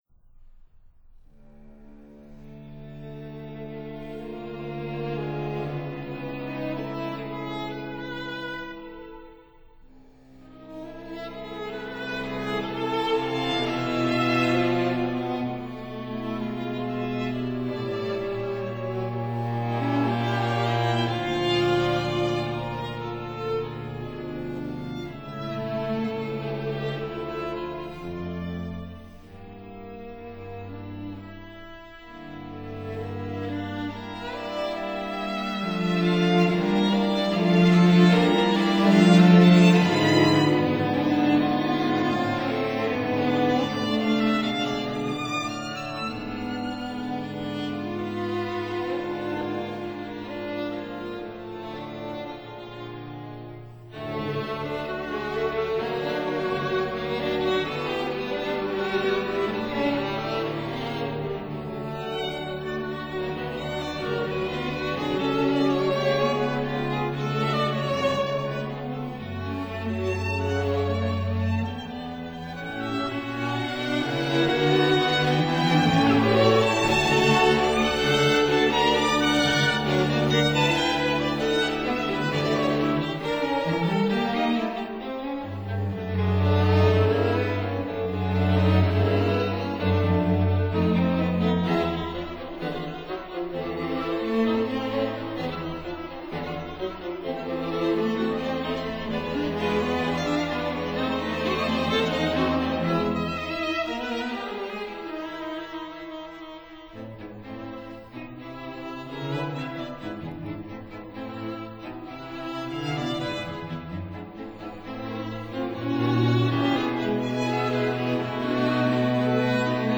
violins
viola
cello